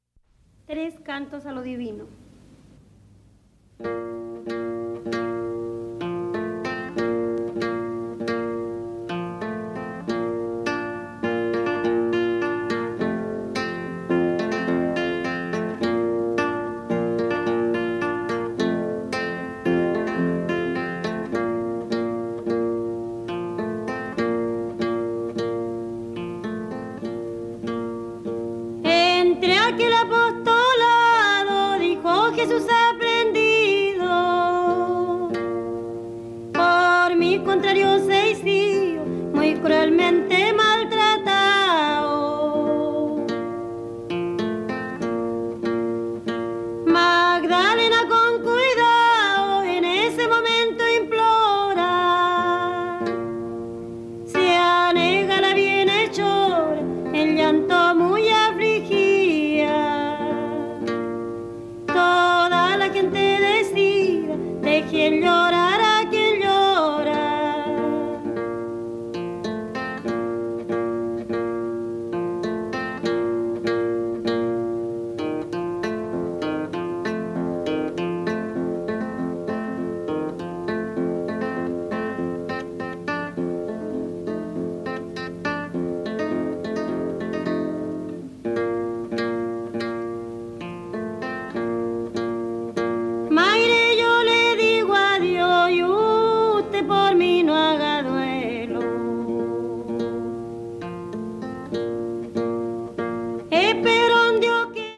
軽やかな舞曲や哀愁を帯びた弔歌などに加えて、チリ民謡のマナーで自作曲をも制作！